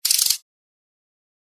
Ratchet.ogg